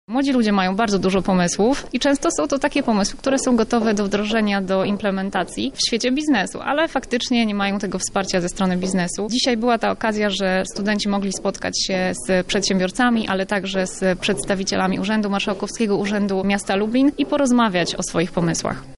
Spotkanie odbyło się w Auli widowiskowej Lubelskiego Parku Naukowo – Technologicznego.